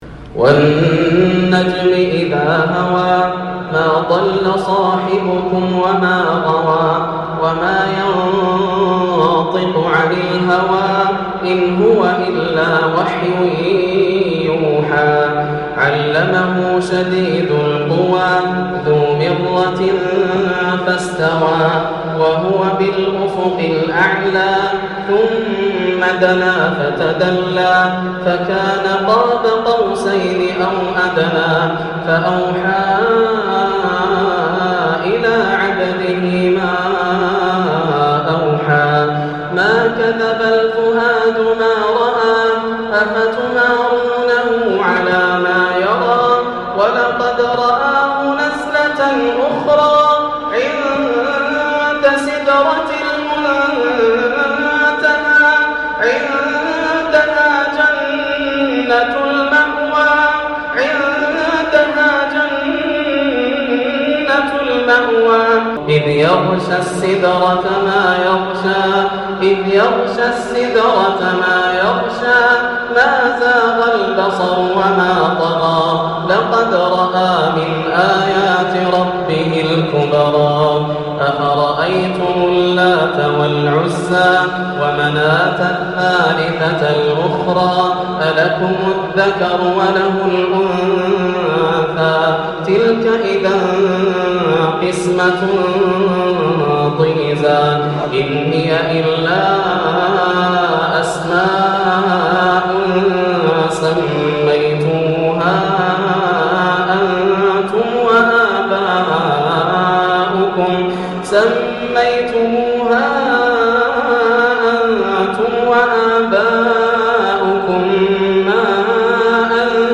سورة النجم > السور المكتملة > رمضان 1430هـ > التراويح - تلاوات ياسر الدوسري